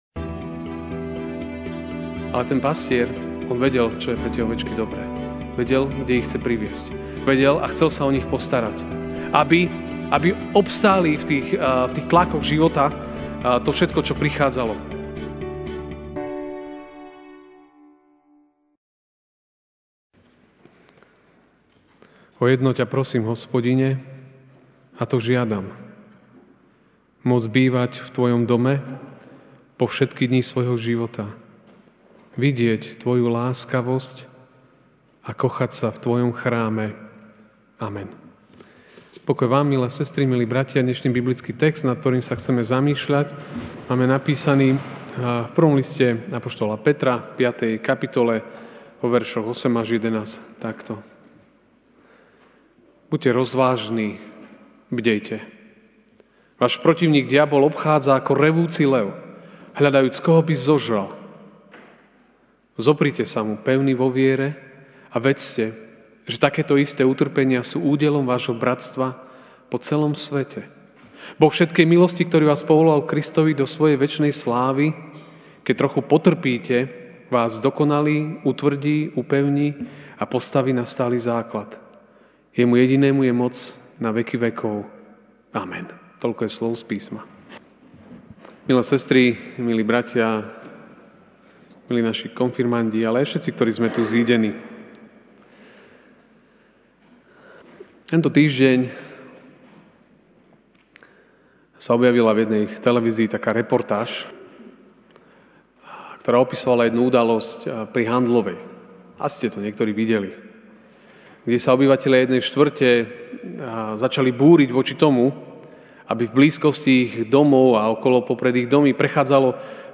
máj 26, 2019 Ty si môj Pastier MP3 SUBSCRIBE on iTunes(Podcast) Notes Sermons in this Series Večerná kázeň: Ty si môj Pastier (1Pt 5, 8-11) Buďte rozvážni, bdejte!